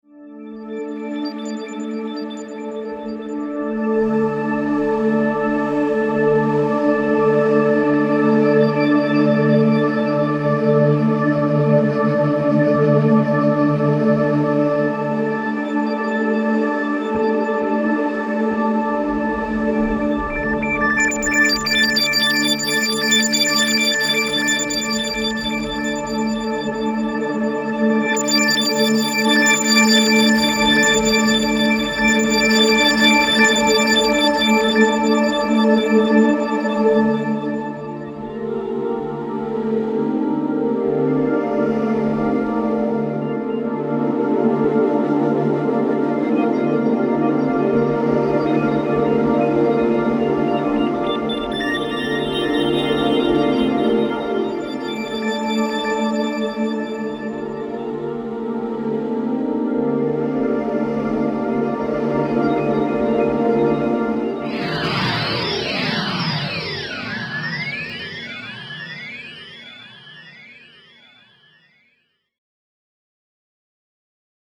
pads
- Long Spheric Ambient Pads -